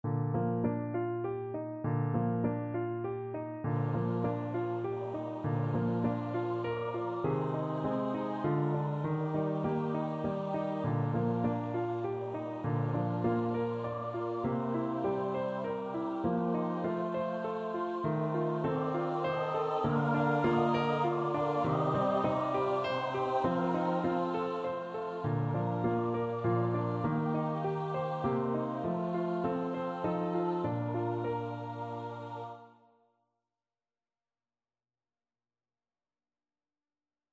Free Sheet music for Choir (SATB)
SopranoAltoTenorBass
17th-century English folk song.
3/4 (View more 3/4 Music)
Bb major (Sounding Pitch) (View more Bb major Music for Choir )
Moderato
Choir  (View more Easy Choir Music)
golden_slumbers_SATB.mp3